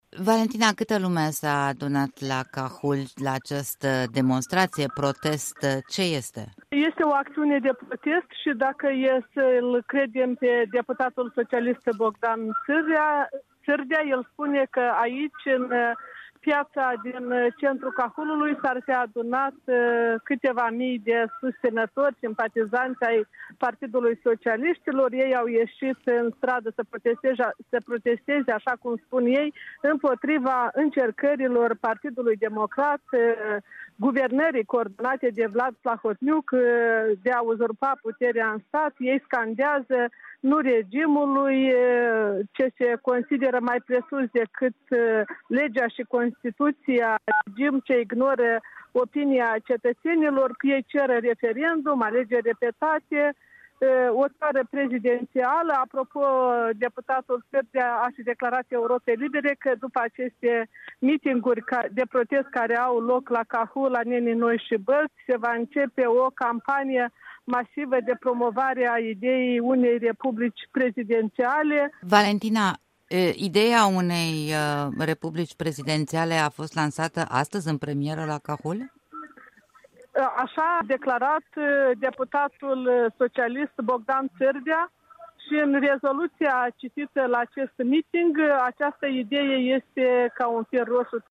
Corespondenții Europei Libere transmit de la Bălți, Anenii Noi și Cahul.